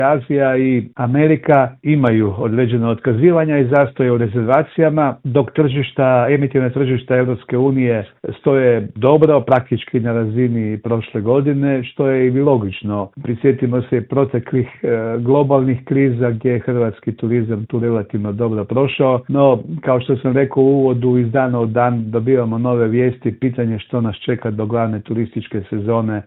u telefonskom Intervjuu Media servisa naglašava da možemo biti optimistični, ali oprezni.